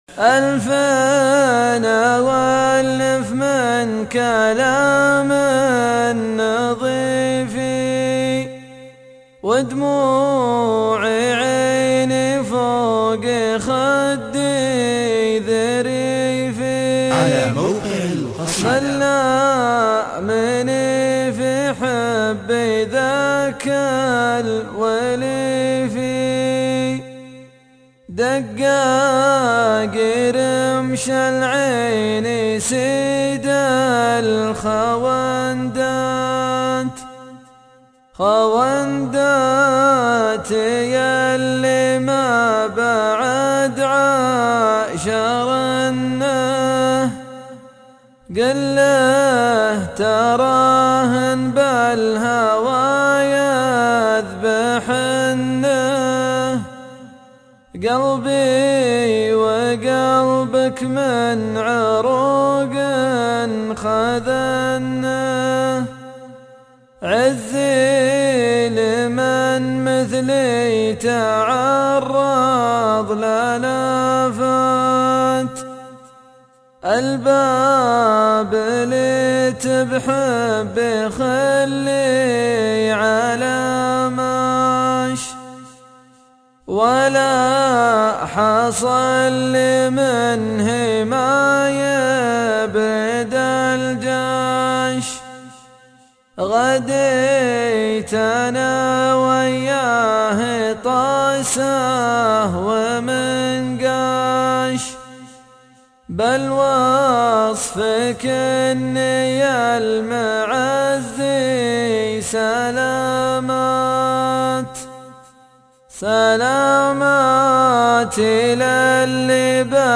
شيله